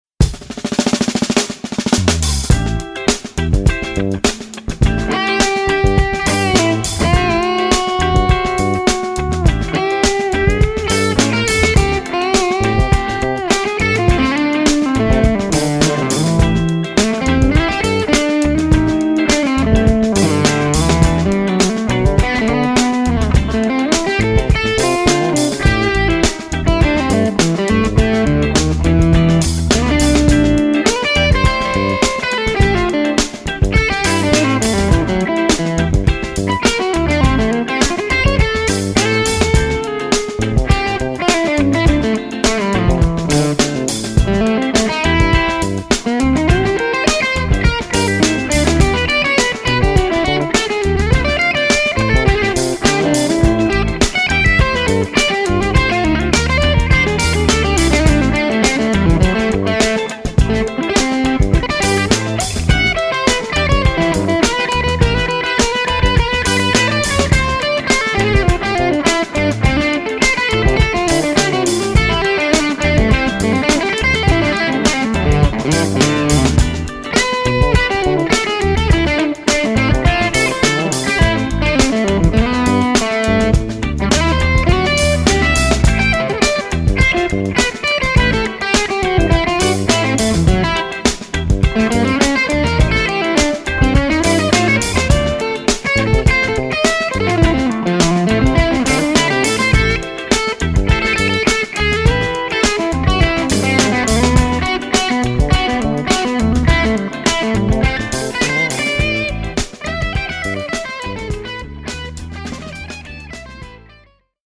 All clips were Eminence RWB speaker and Audix I5 mic.